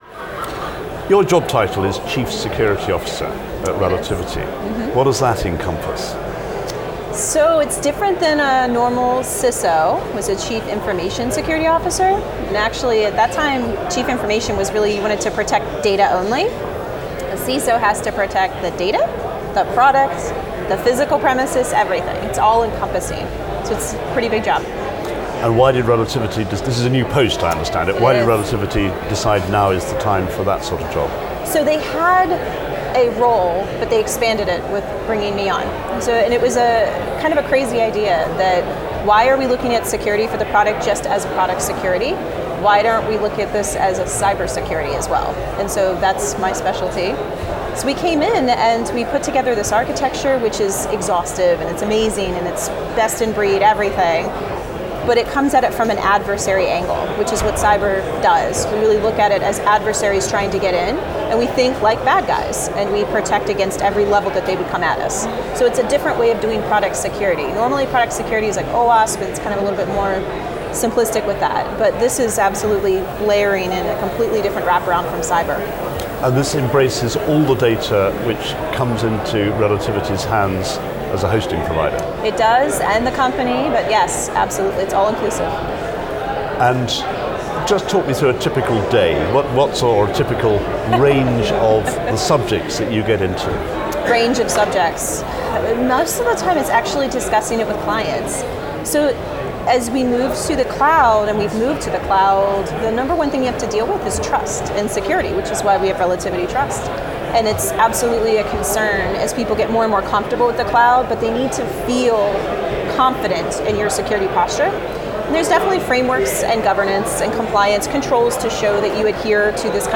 Interview
At Relativity Fest in London